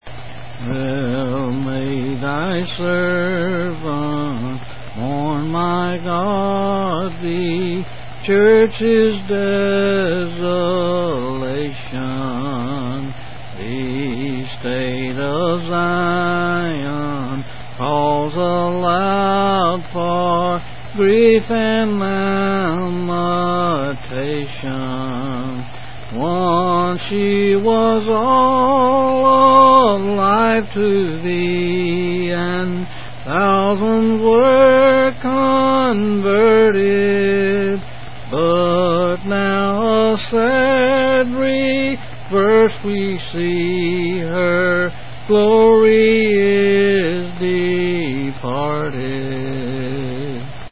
8s and 7s.